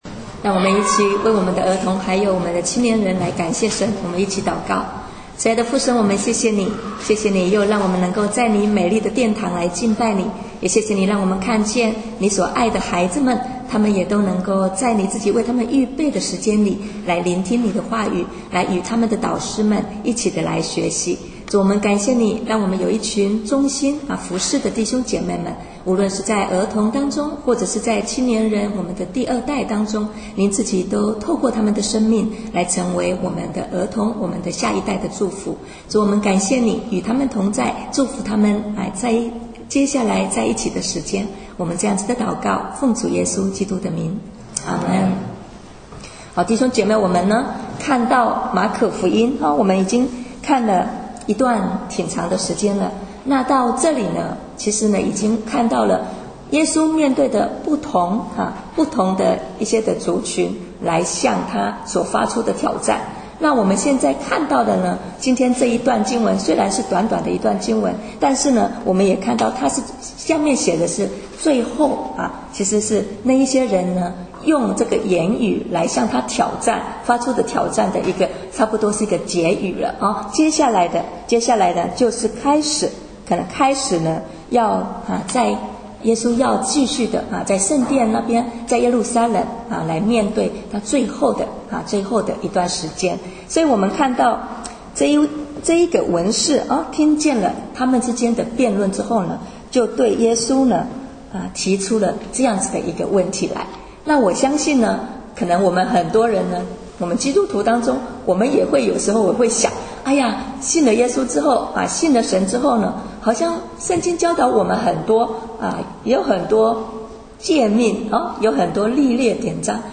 主日讲道音频